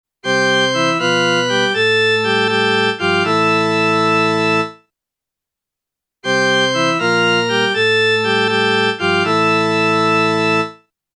To demonstrate the removal of the tritone, the soundfile below plays the phrase in box 6, from the beginning of the bar prior to the first time bar, first with the b in the tenor remaining natural and the tritone unchanged, then again with the b flattened and the tritone removed.